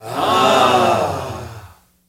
Ahhs | Sneak On The Lot